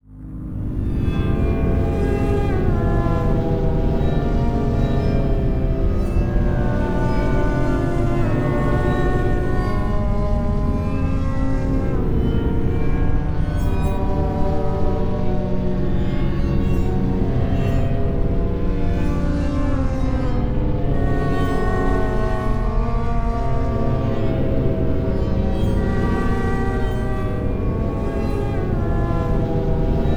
MOURNFULL.wav